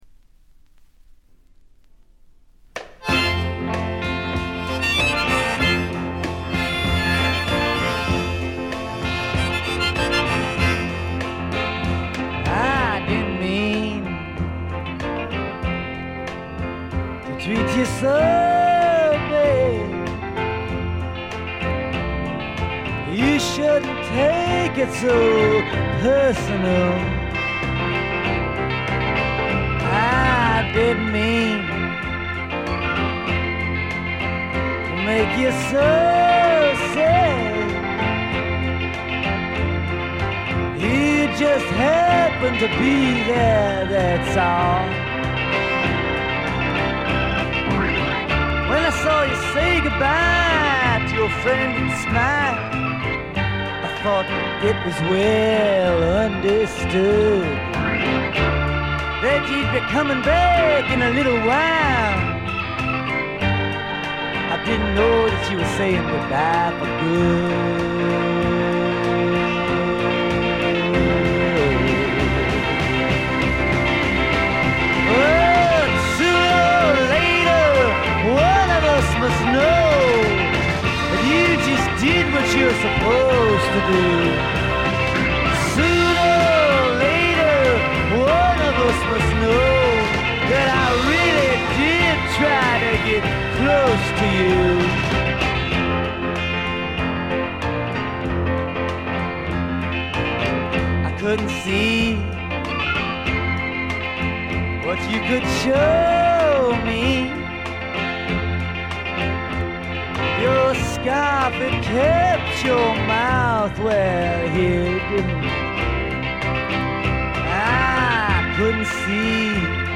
A面最後フェードアウト終了間際からとB1中盤で少々チリプチ。
試聴曲は現品からの取り込み音源です。
vocals, guitar, harmonica, piano